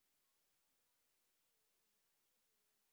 sp14_train_snr10.wav